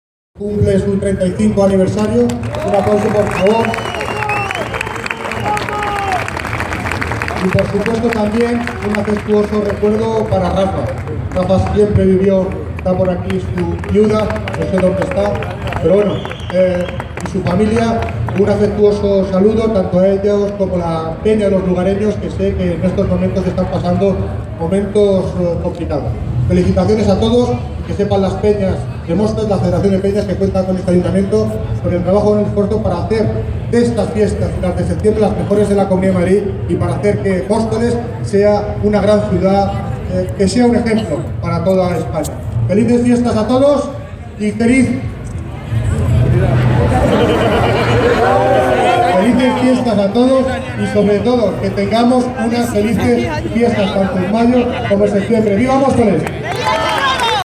Sonido - David Lucas (Alcade de Móstoles) durante la entrega de Premios de la Federación
David Lucas durante la entrega de Premios de la Federación.mp3